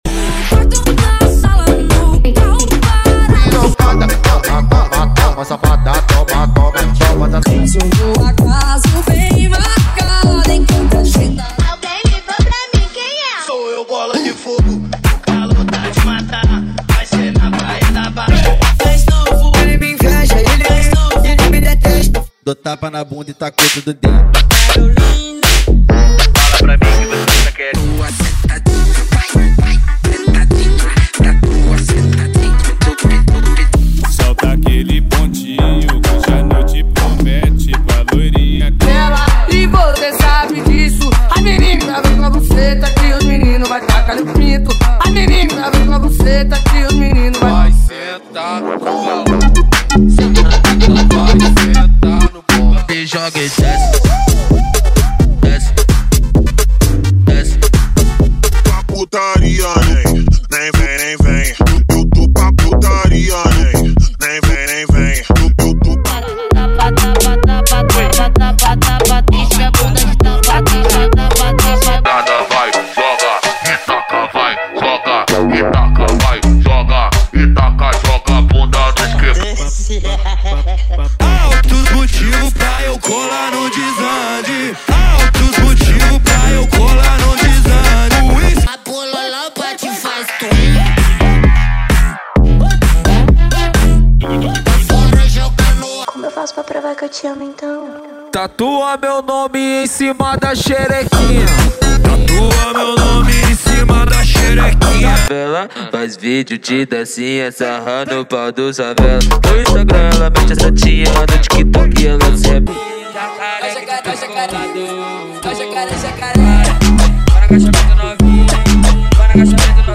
• Sem Vinhetas
• Em Alta Qualidade